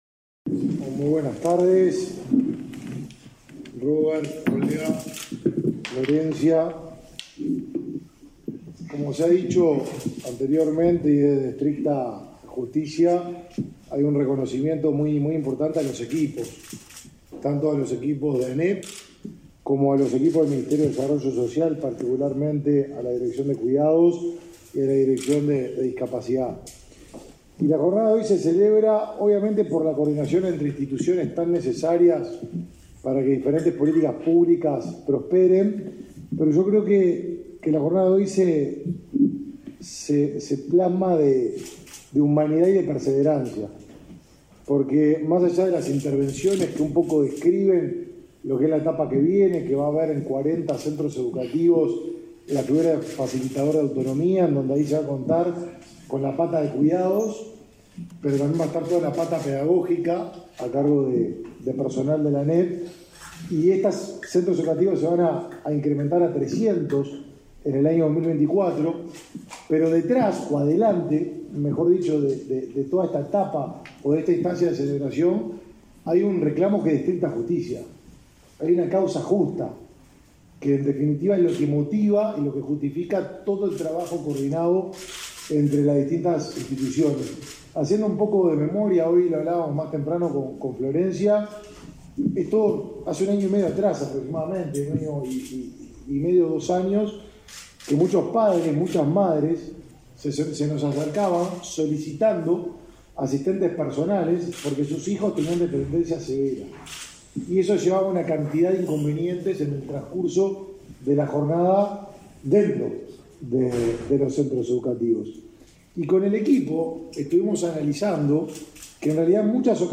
Conferencia de prensa por presentación de facilitador que brindará atención a estudiantes con dependencia
El ministro de Desarrollo Social, Martín Lema, y el presidente de la Administración Nacional de Educación Pública, Robert Silva, presentaron, este 21